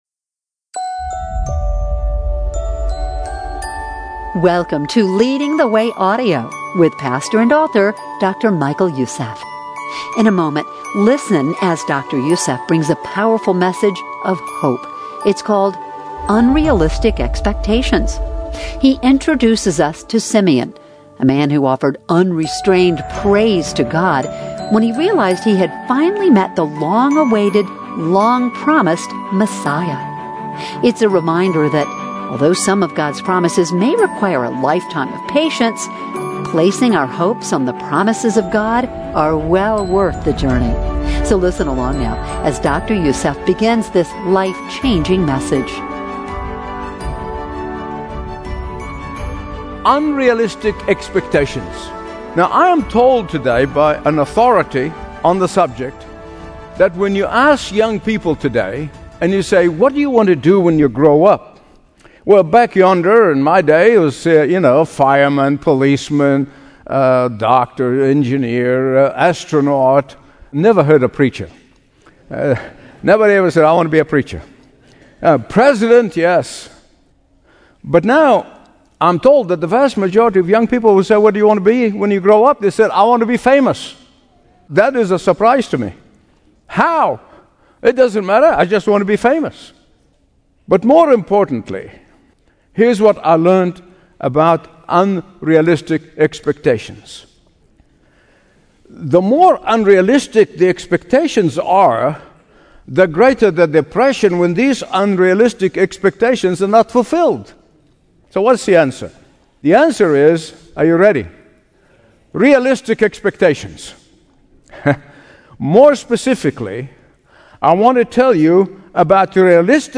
Dr. Michael Youssef teaches from Luke 2:25-32, the story of Simeon, a godly man who praised God when he met his long-awaited, long-promised Messiah. This sermon illustrates the principle that though it may take longer than we anticipate, our expectations will be satisfied when we place the whole of our hopes on the promises of God and His Word.